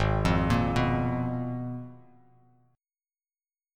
G#m6add9 chord